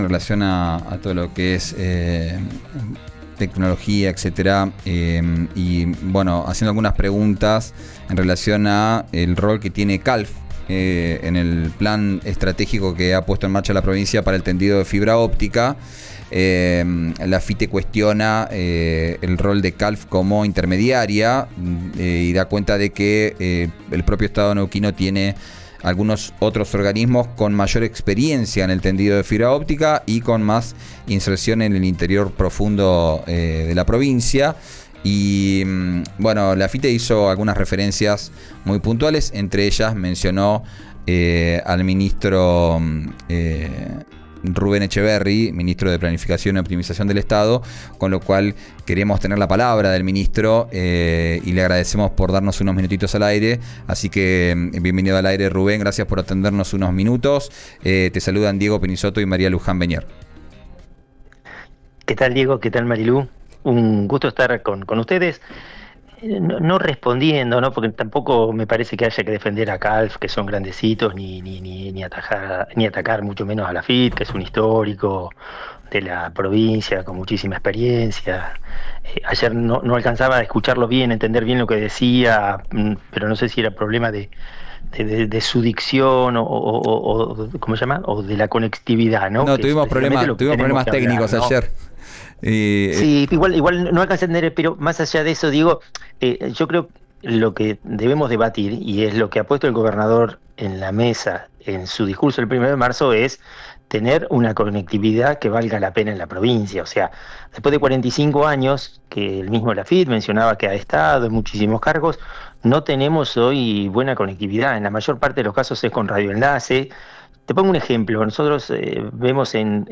Escuchá a Rubén Etcheverry, ministro de Planificación de Neuquén, por RÍO NEGRO RADIO: